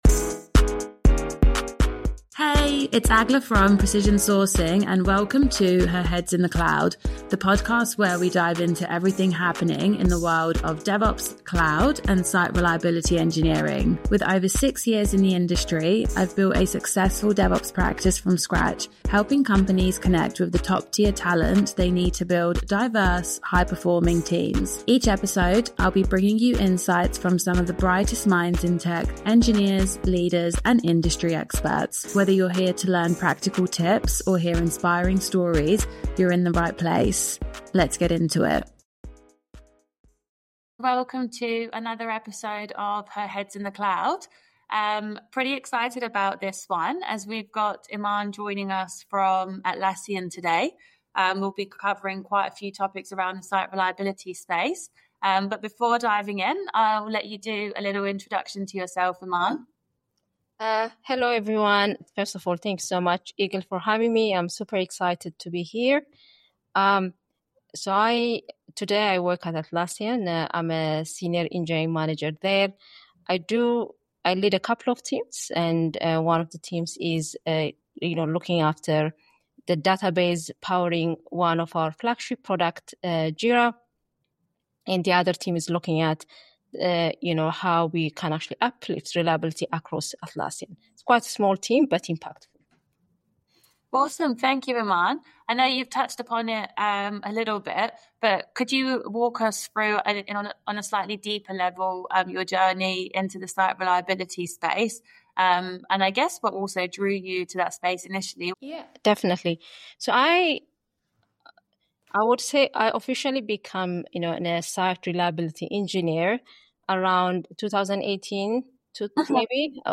Tune in to discover insights on handling high-stakes incidents, fostering a no-blame culture, and the future of SRE with AI advancements. Don't miss this engaging conversation filled with practical advice and forward-thinking perspectives.